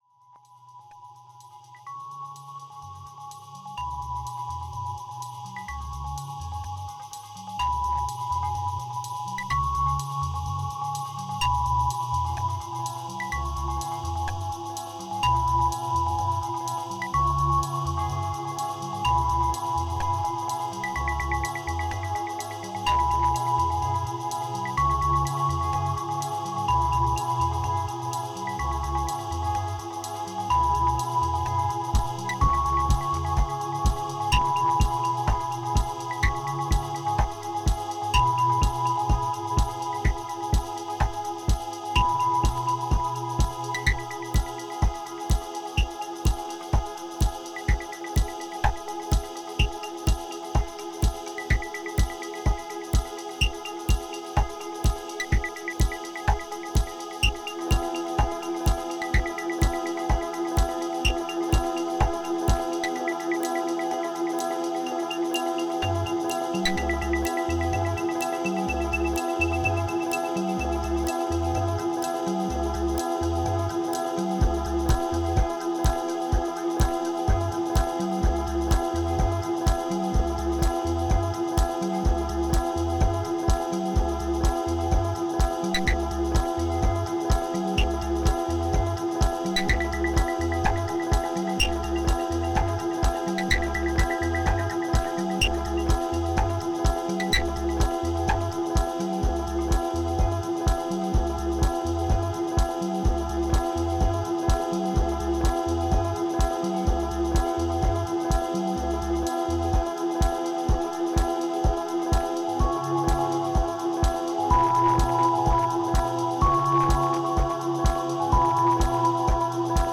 2451📈 - 87%🤔 - 126BPM🔊 - 2017-05-28📅 - 610🌟